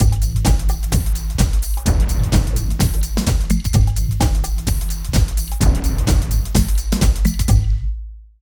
50 LOOP   -L.wav